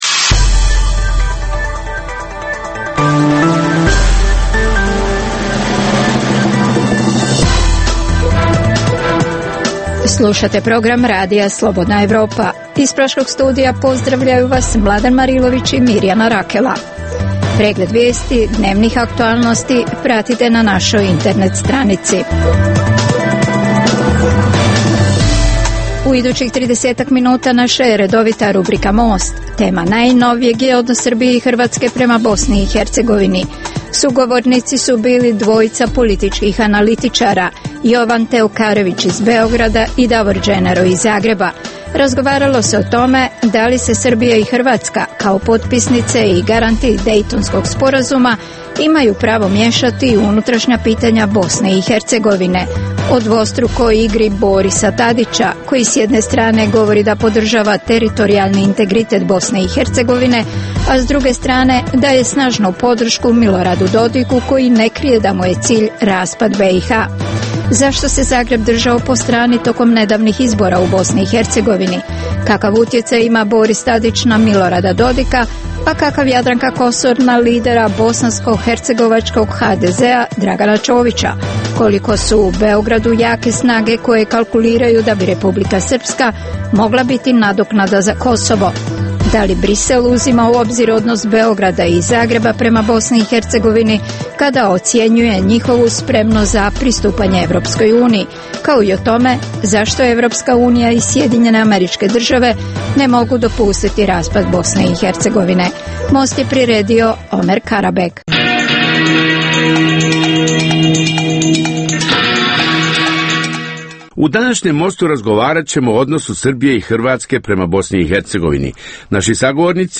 dvojica političkih analitičara